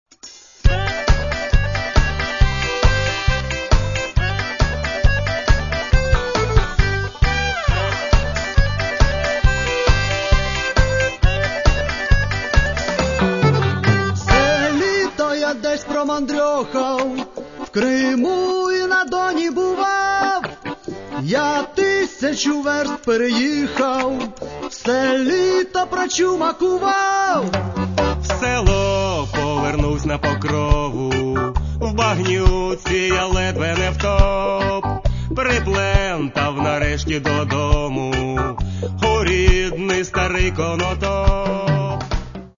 Каталог -> Народна -> Сучасні обробки